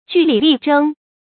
据理力争 jù lǐ lì zhēng
据理力争发音
成语正音据，不能读作“jǔ”。